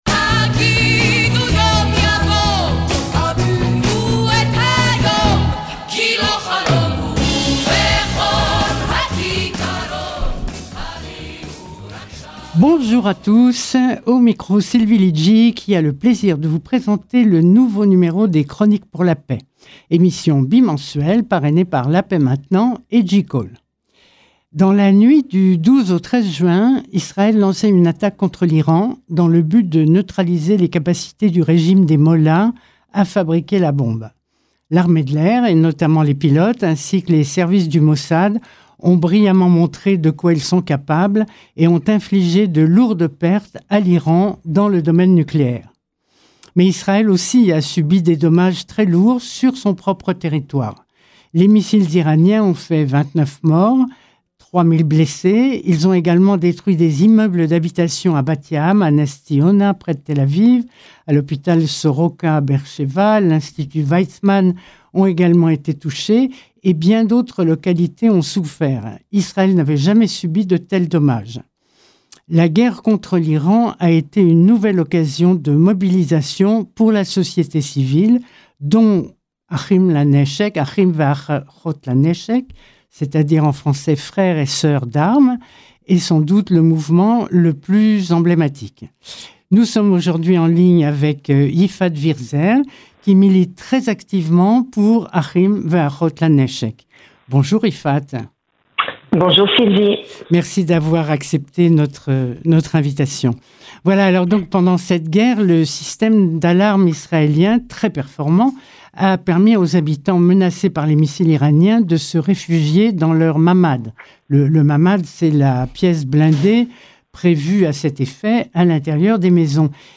émission bimensuelle sur Radio Shalom